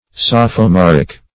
sophomoric - definition of sophomoric - synonyms, pronunciation, spelling from Free Dictionary
Sophomoric \Soph`o*mor"ic\, Sophomorical \Soph`o*mor"ic*al\, a.